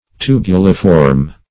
Search Result for " tubuliform" : The Collaborative International Dictionary of English v.0.48: Tubuliform \Tu"bu*li*form`\, a. [Cf. F. tubuliforme.] Having the form of a small tube.